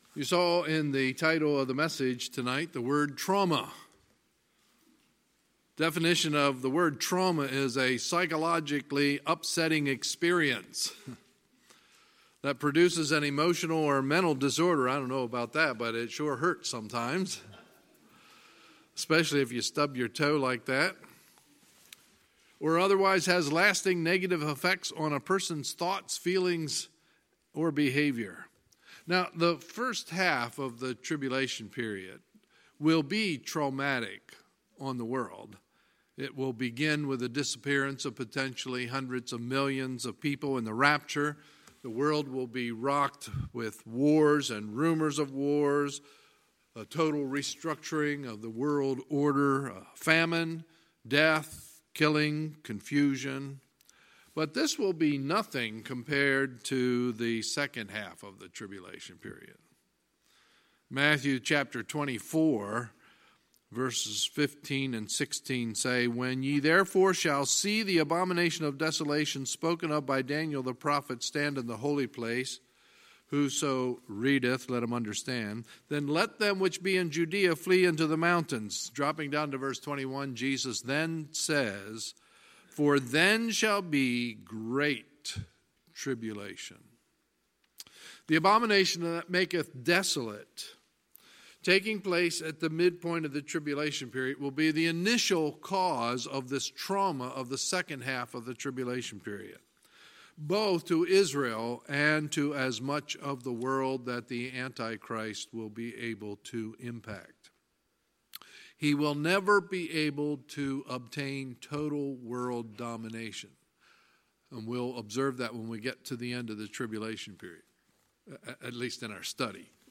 Sunday, April 7, 2019 – Sunday Evening Service